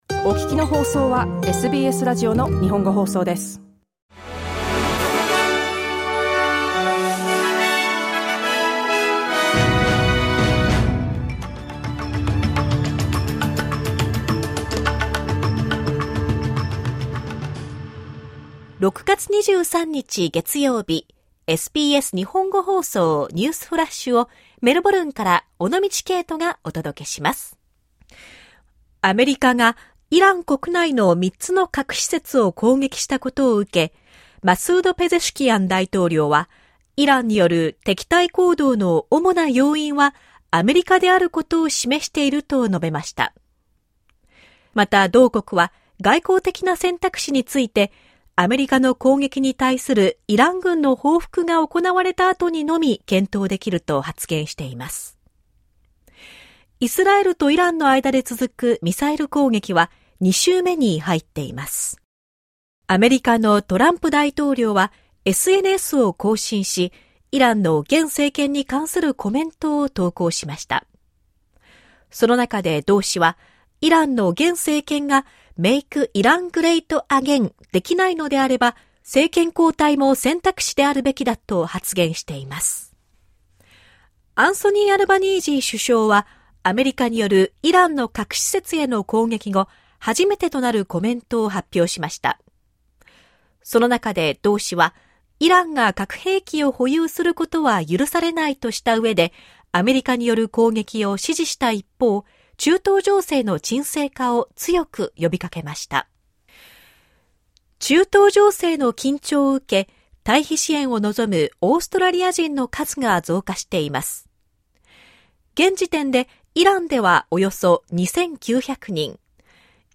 SBS日本語放送ニュースフラッシュ 6月23日 月曜日